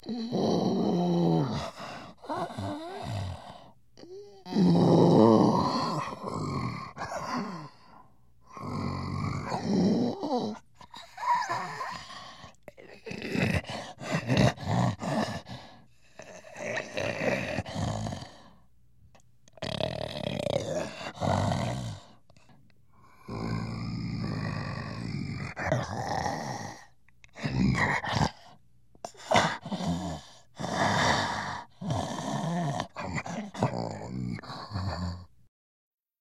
gorilla-sound